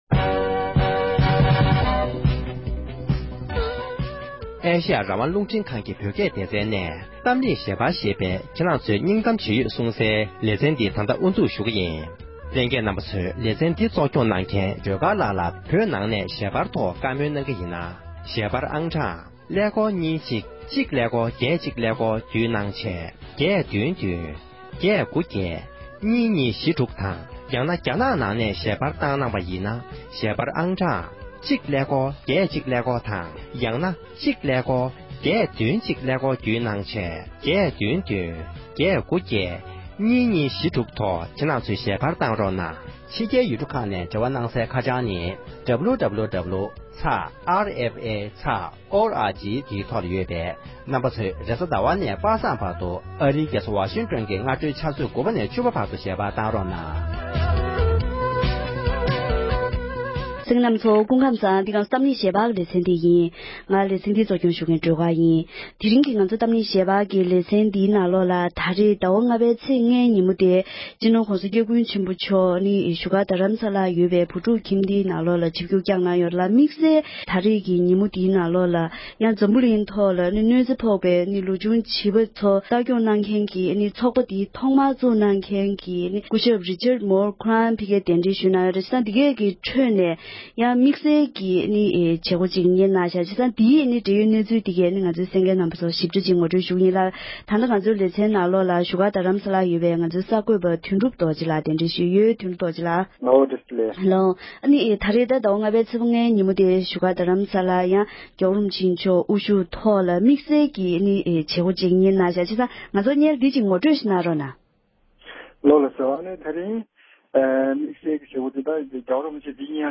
བཟོད་པའི་སྐོར་བཀའ་སློབ།